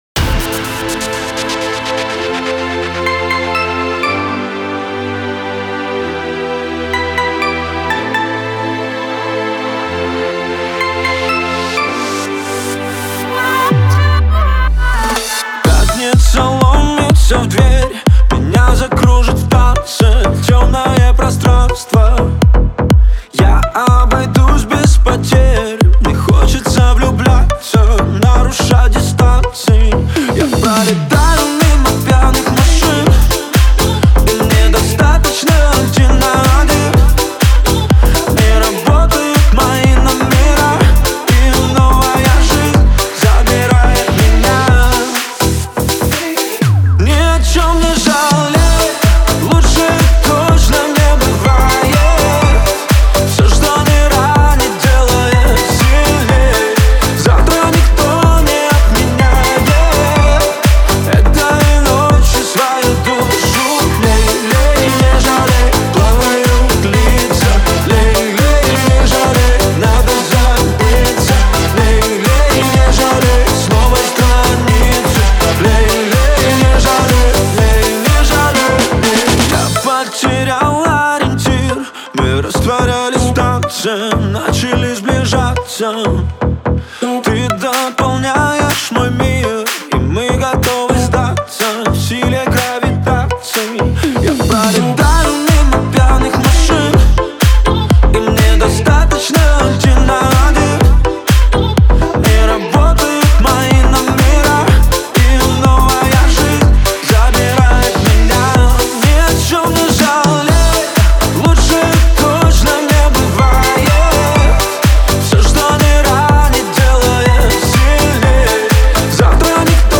яркая и зажигательная поп-песня